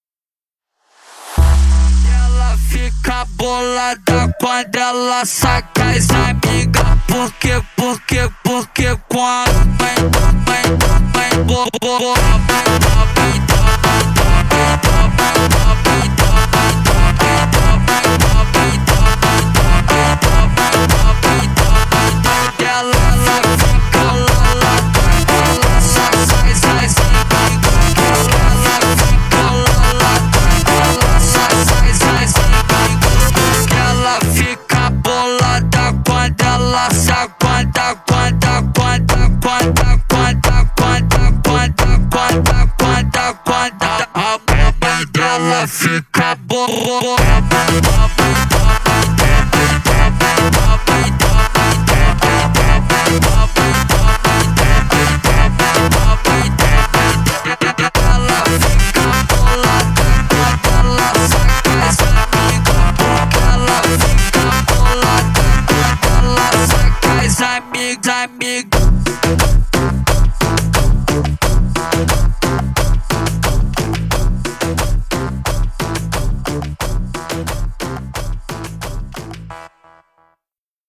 Качество: 192 kbps, stereo
Slowed version